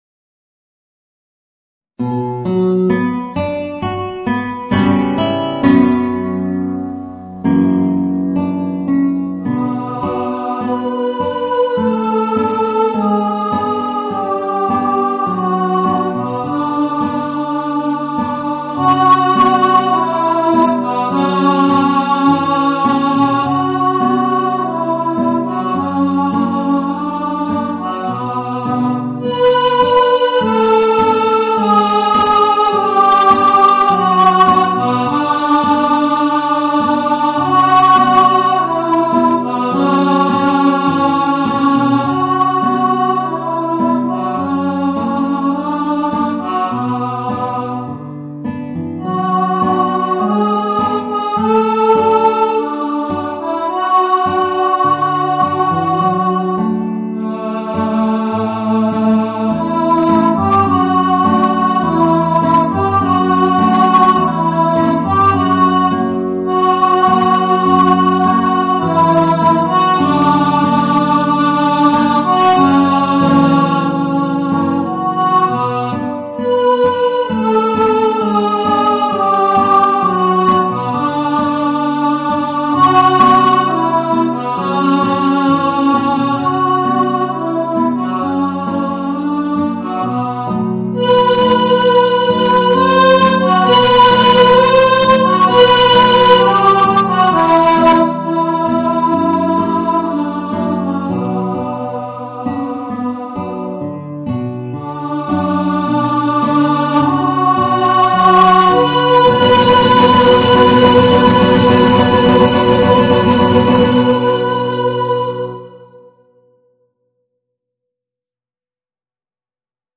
Voicing: Guitar and Alto